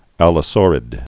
(ălə-sôrĭd)